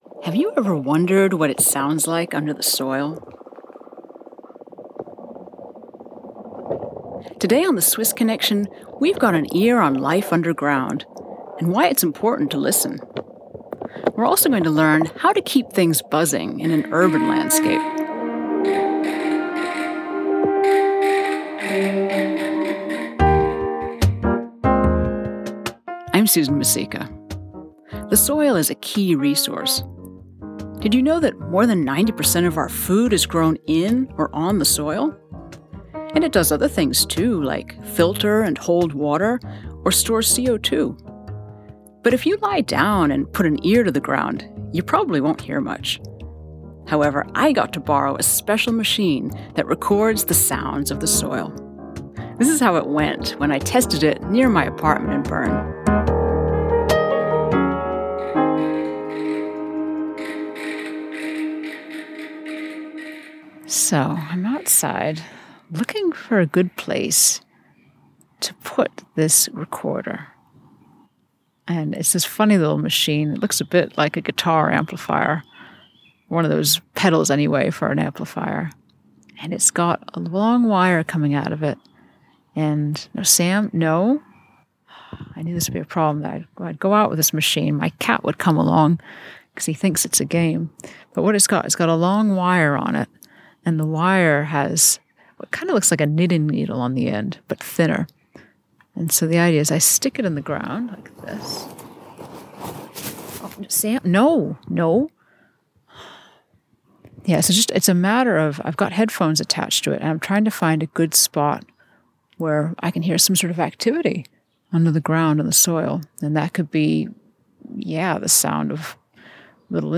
In this episode we hear the sound of soil life and take a tour of greenspaces in Zurich.
Discussion about urban biodiversity as well as life within the soil.